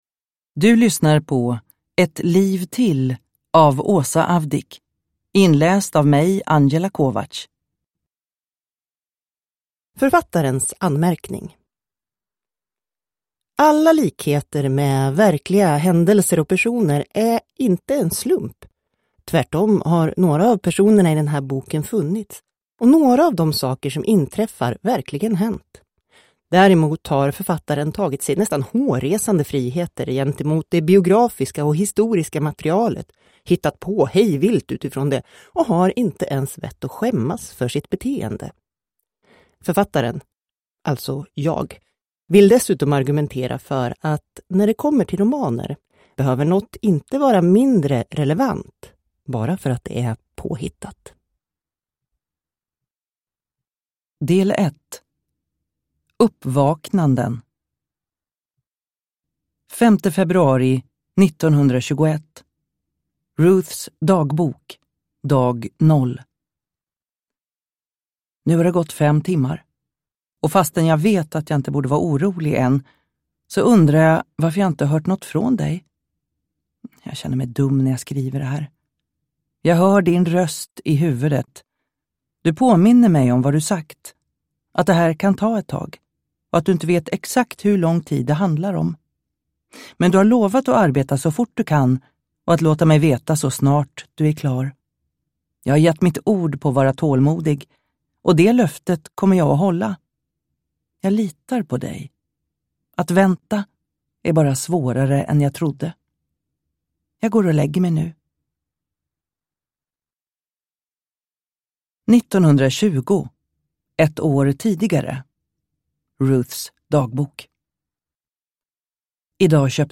Ett liv till – Ljudbok – Laddas ner